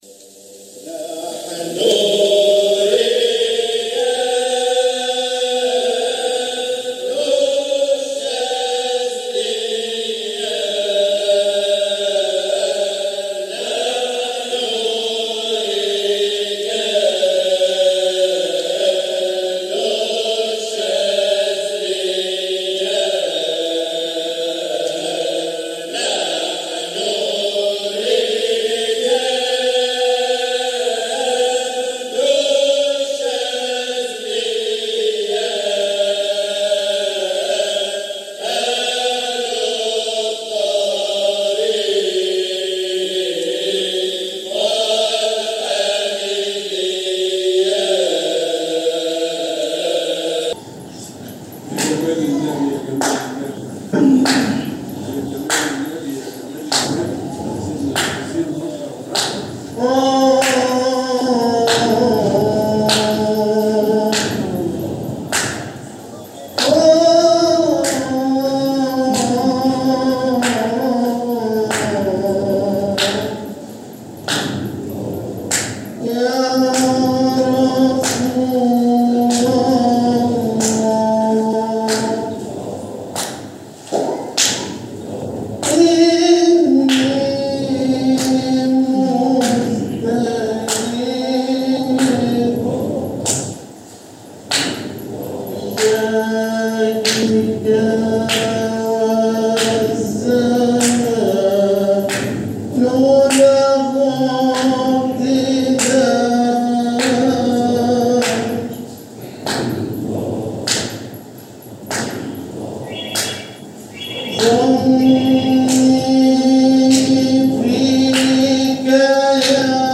جزء من حلقة ذكر بمسجد مولانا المؤسس قُدس سره